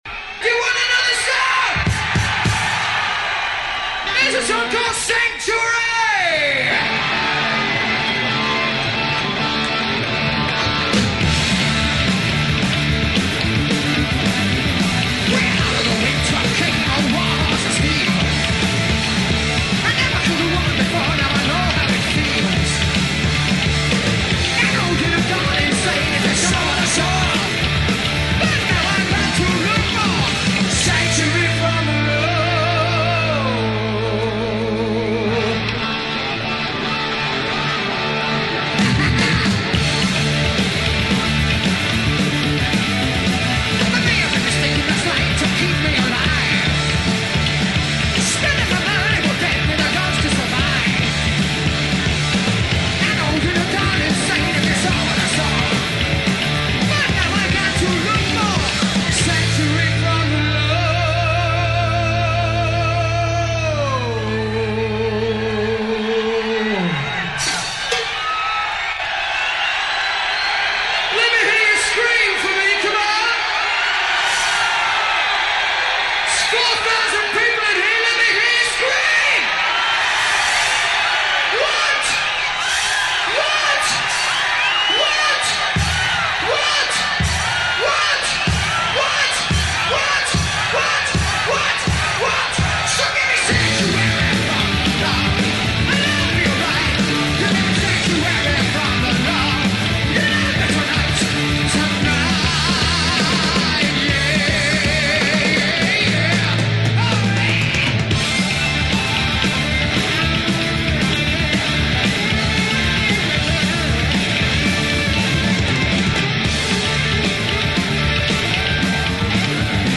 Live Concert NWOBHM